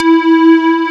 SQUAREE5.wav